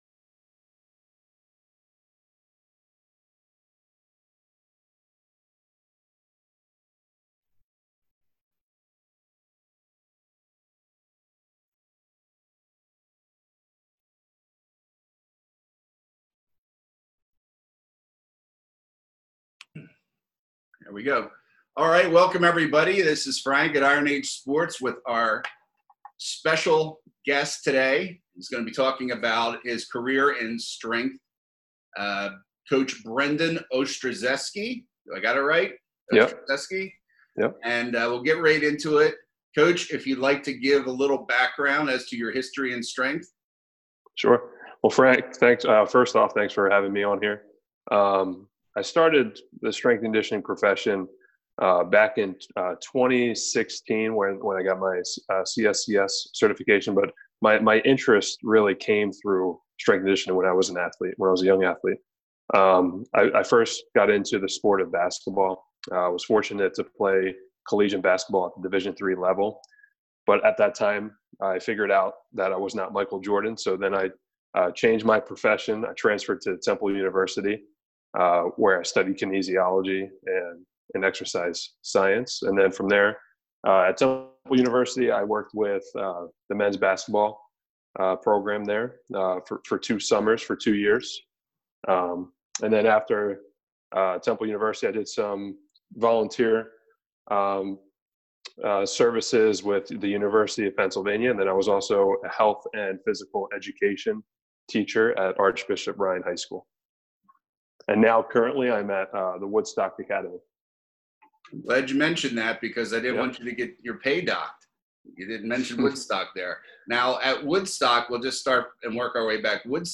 Interview: Do you want a future in Strength Coaching??